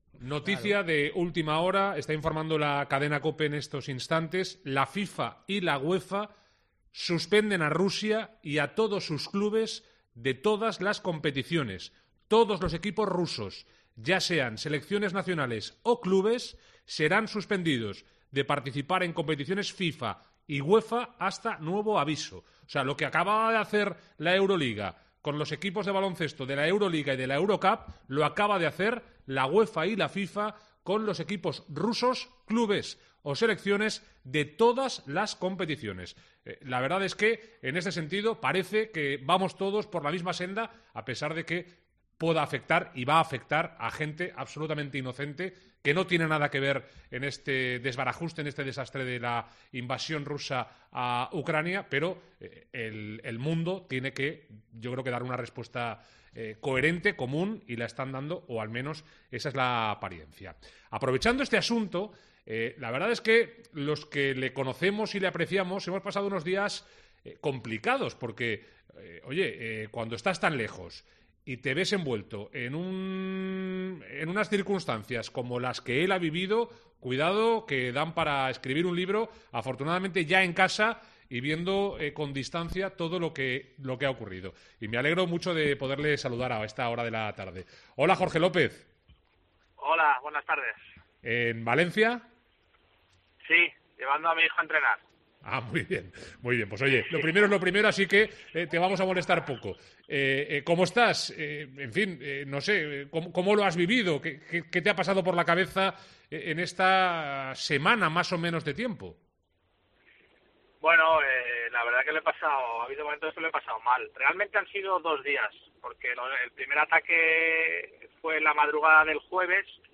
AUDIO. Entrevista